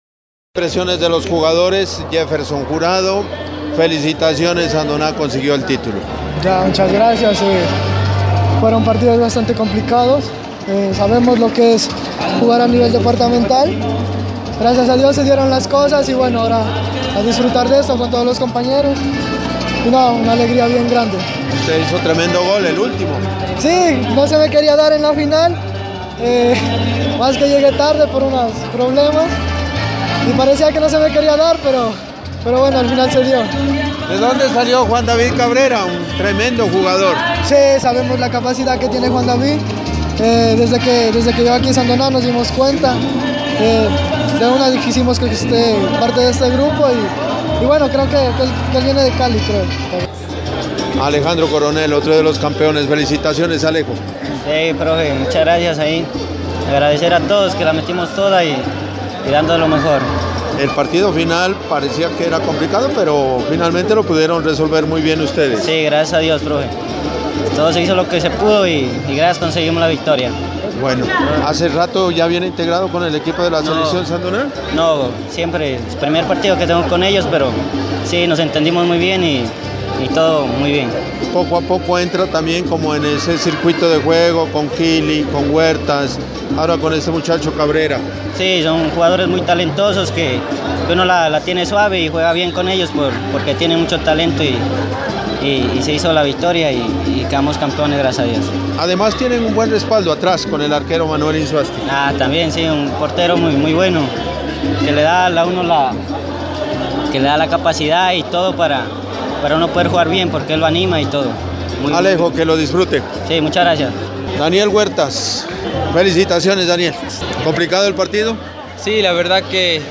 Entrevista con los jugadores campeones: